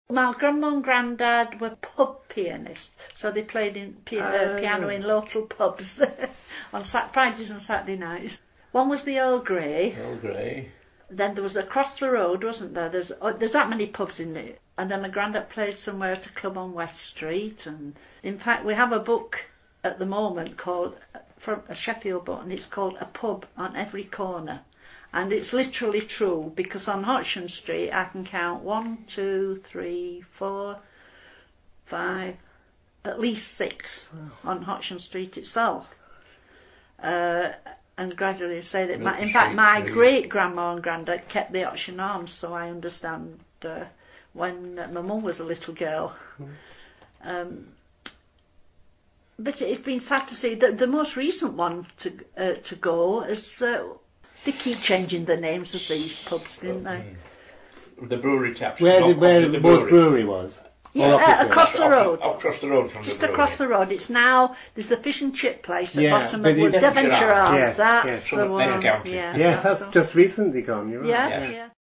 In these audio clips below, past and present residents recollect the names of some of these ‘disappeared’ pubs, and tell some stories about what used to go on…